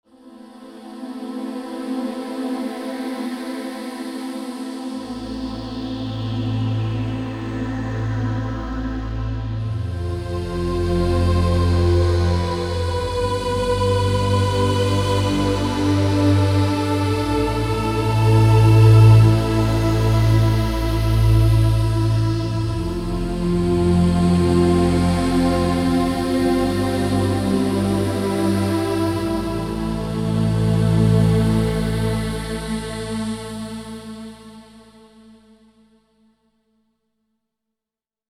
A large collection of mystical Choir and Vocal sounds that will take you on a magical journey.